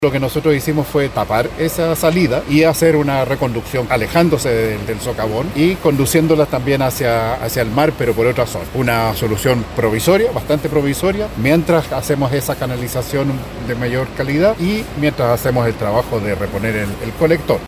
Radio Bío Bío estuvo en las inmediaciones del socavón contiguo al edificio Kandinsky, donde se encuentra trabajando personal del Ministerio de Obras Públicas.
En ese sentido, el seremi de la cartera, Yanino Riquelme, adelantó que ya cuentan con una solución provisoria.